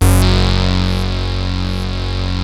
OSCAR F#2 2.wav